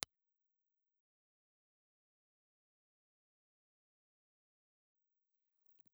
Impulse Response File:
IR file for a National WM-701 ribbon microphone.
National_WM701_IR.wav